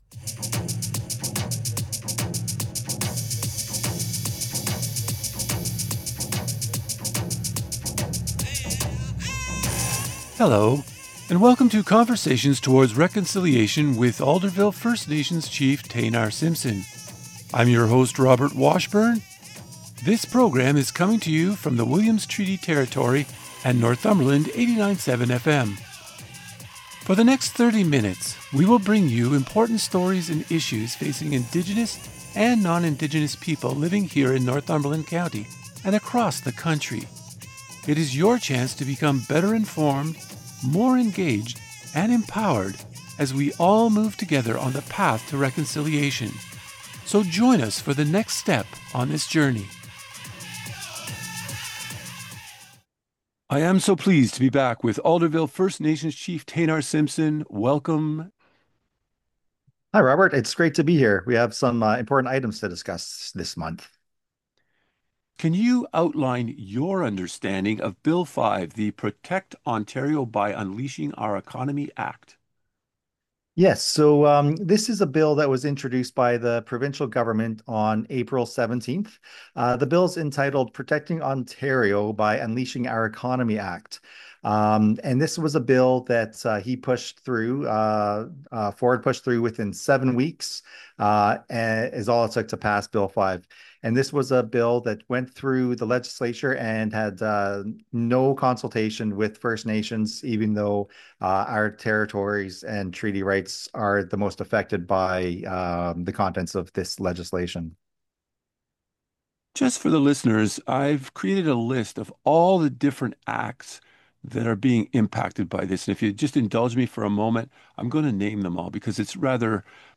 Alderville First Nations Chief Taynar Simpson expresses deep concerns with Bill 5, the Protect Ontario by Unleashing our Economy Act, in this interview.